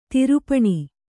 ♪ tirupaṇi